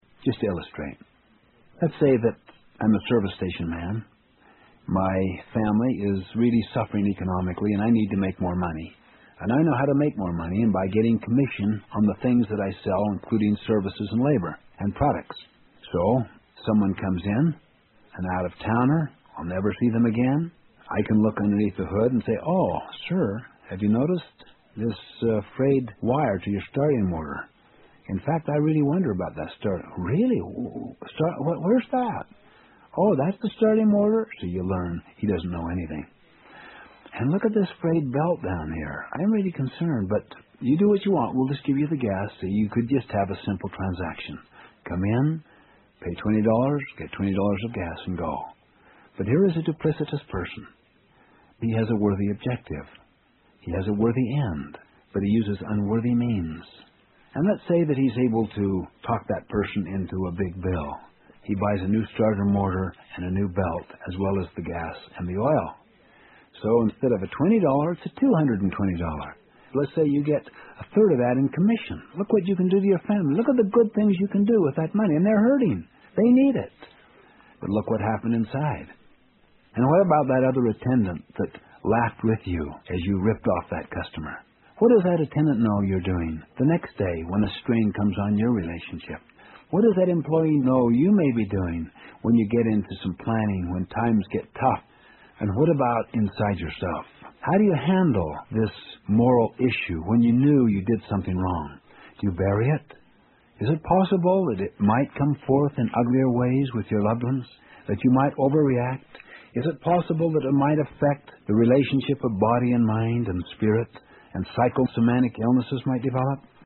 有声畅销书：与成功有约15 听力文件下载—在线英语听力室